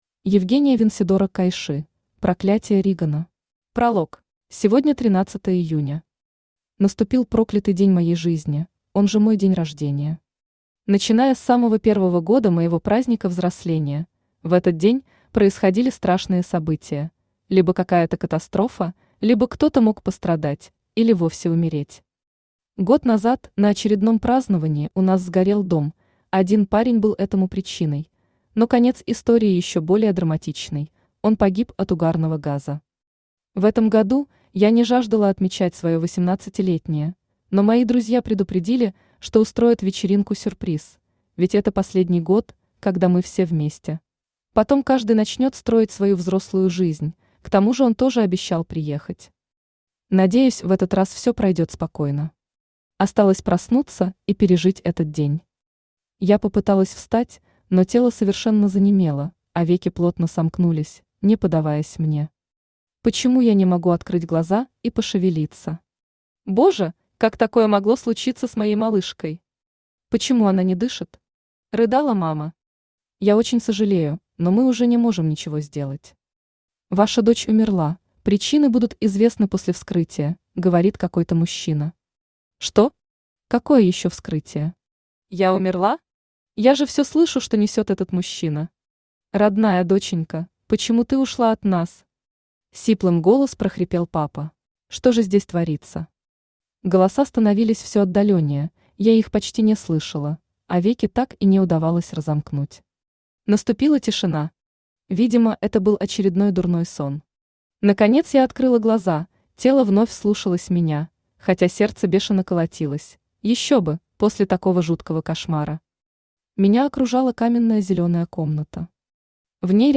Аудиокнига Кайши. Проклятие Ригана | Библиотека аудиокниг
Проклятие Ригана Автор Евгения Александровна Венседора Читает аудиокнигу Авточтец ЛитРес.